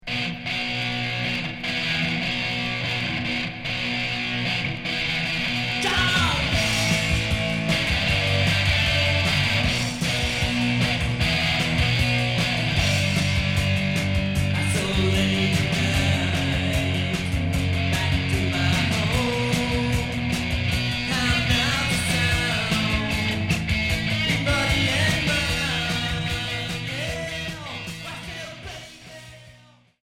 Rock garage Unique 45t retour à l'accueil